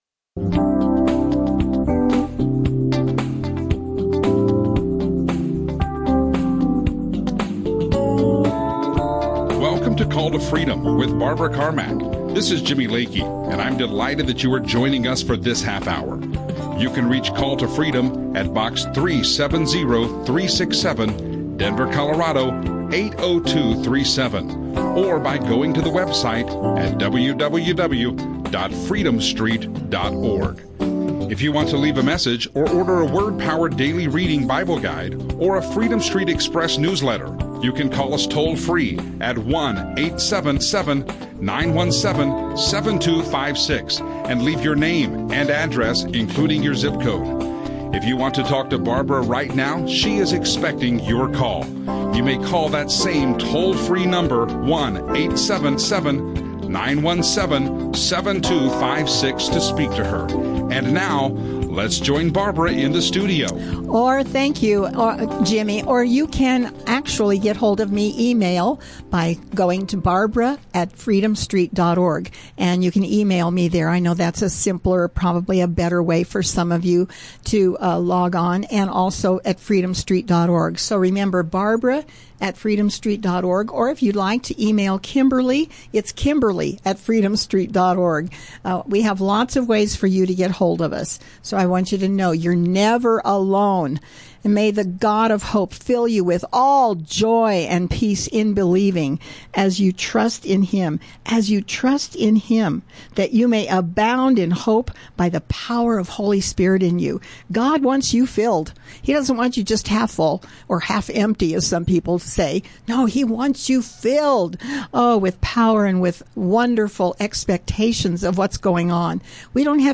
Christian radio
Radio shows